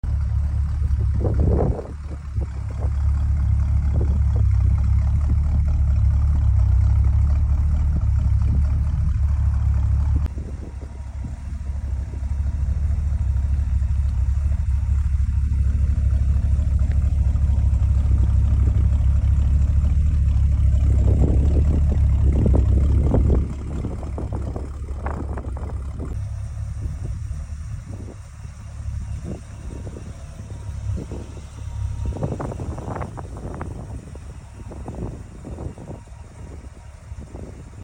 Now sold! 1981 Chevrolet Corvette sound effects free download